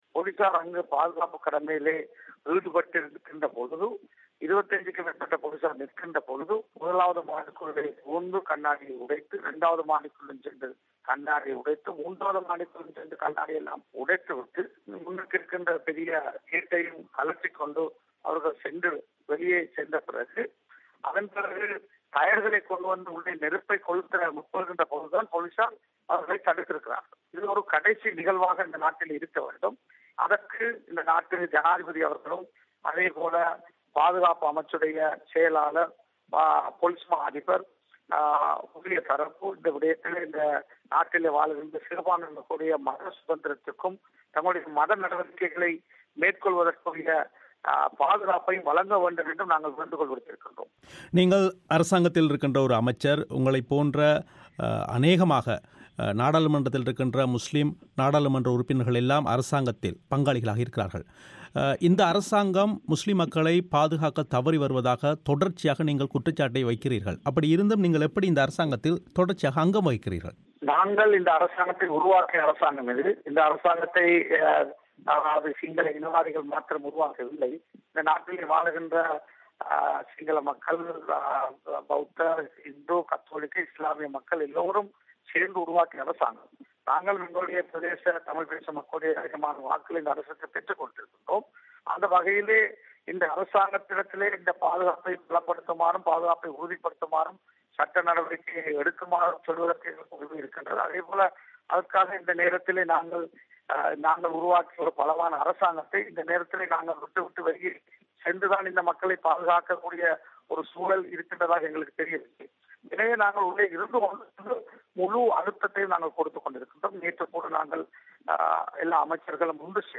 இலங்கையில் பள்ளிவாசல்கள் மீதான தொடர் தாக்குதல்கள் தொடர்பில் முஸ்லிம் அமைச்சர்கள் ஜனாதிபதிக்கு கடிதம் எழுதியுள்ளமை பற்றி தமிழோசை எழுப்பிய கேள்விகளுக்கு அமைச்சர் ரிசாத் அளித்த பதில்கள்